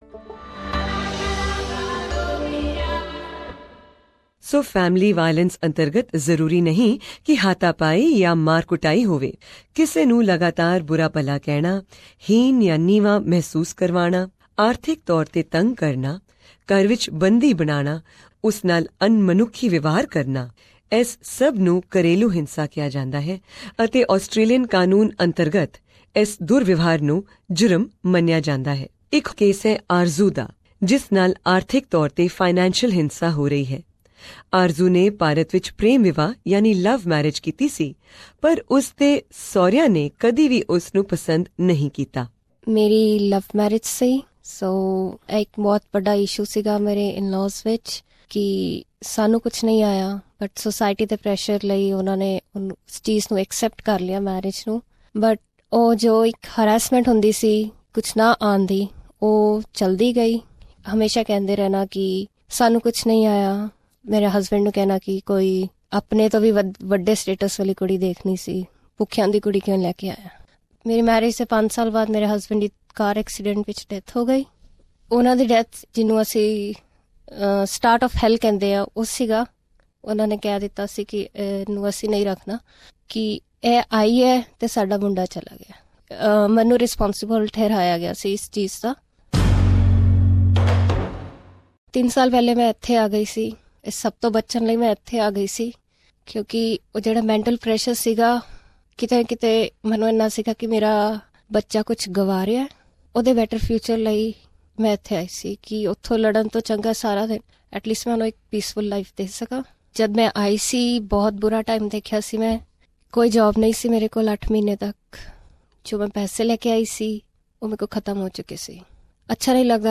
And in this episode, we also have a male victim sharing his experiences with us.
This is the fourth episode of our multi-award winning documentary on family violence in the Indian community of Australia, The Enemy Within.